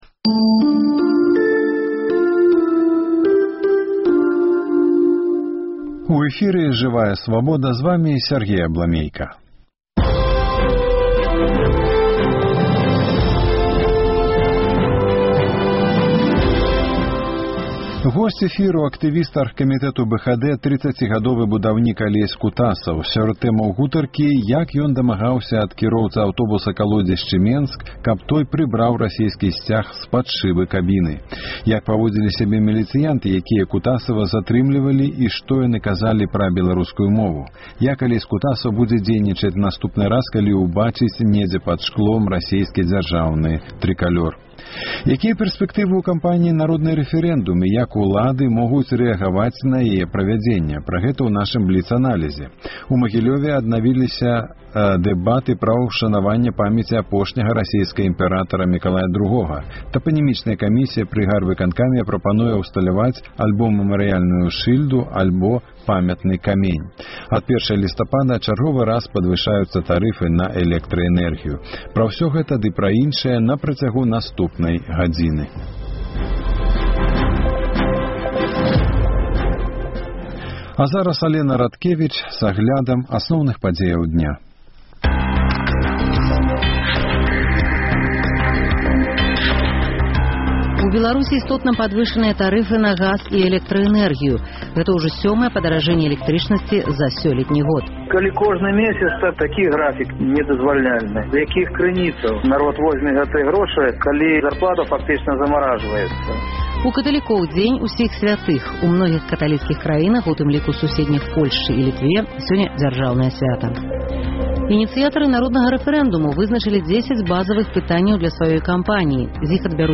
Госьць эфіру